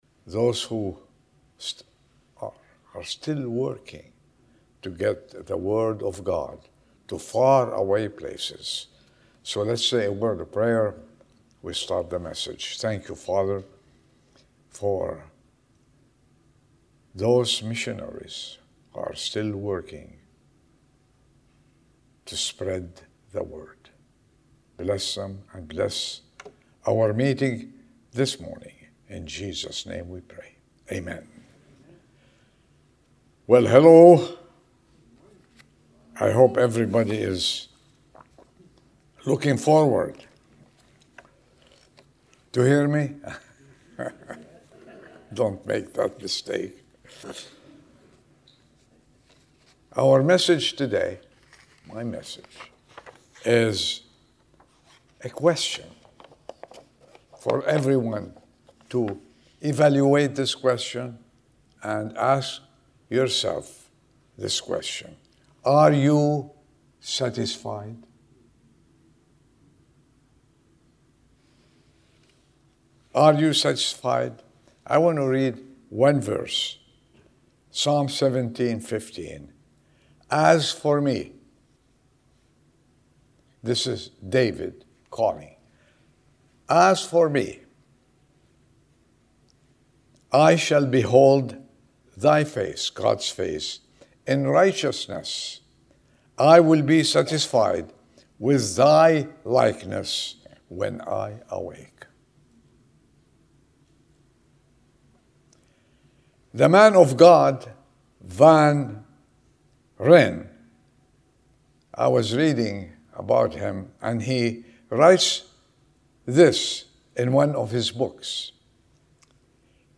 Sermon 10/26/2025 Are You Satisfied?